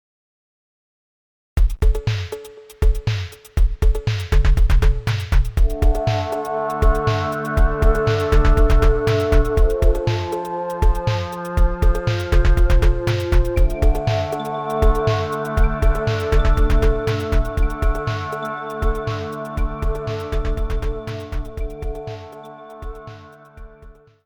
Audiorecording, Musik & Sounddesign